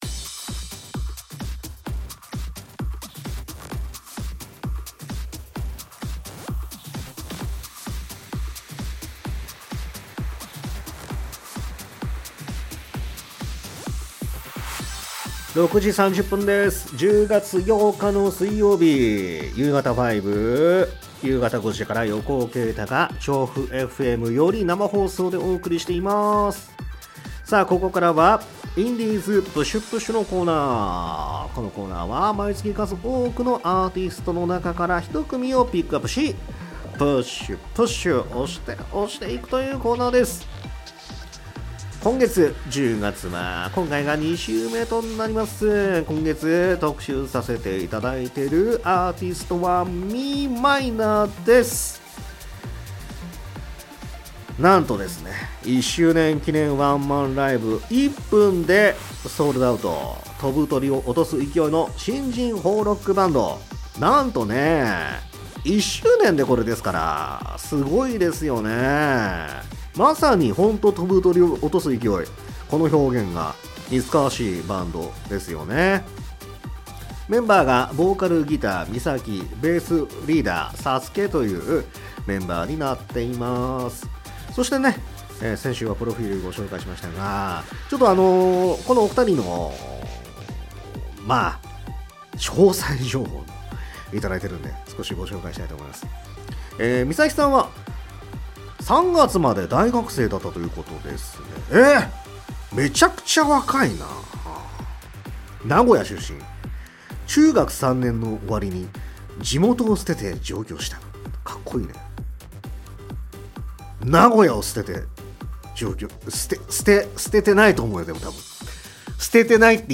※アーカイブでは楽曲カットしています